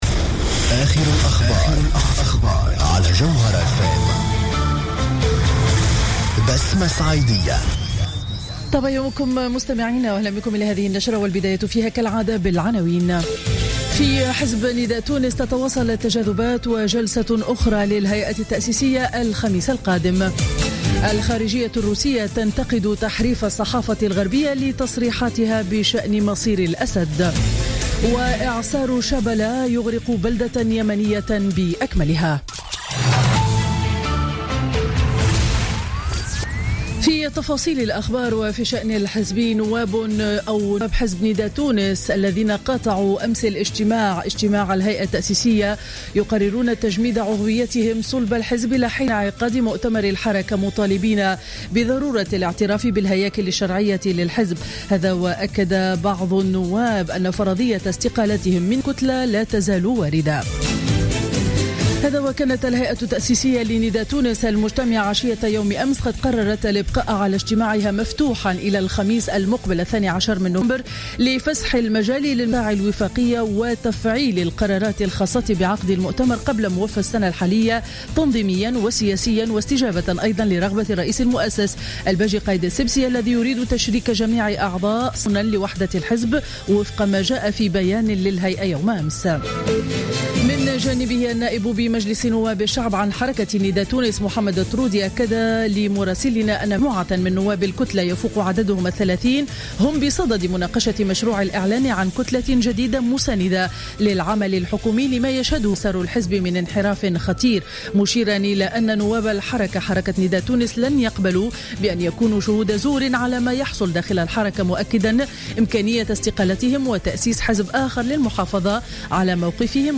نشرة أخبار السابعة صباحا ليوم الأربعاء 4 نوفمبر 2015